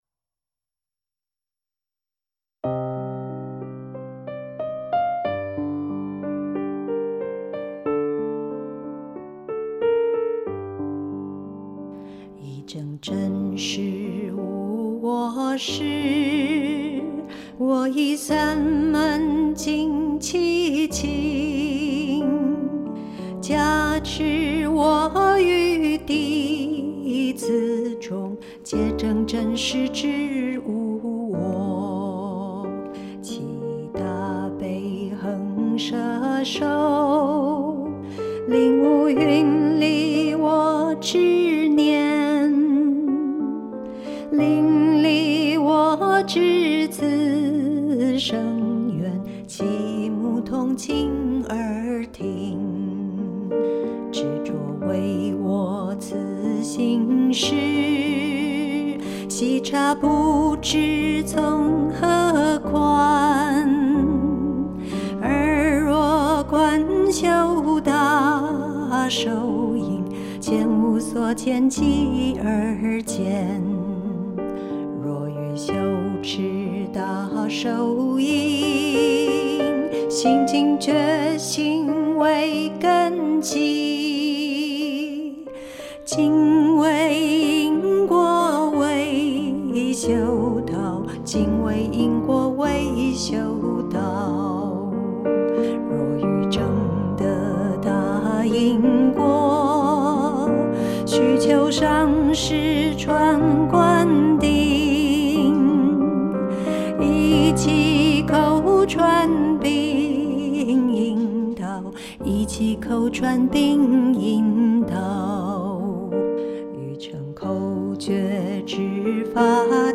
鋼琴配樂